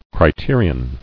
[cri·te·ri·on]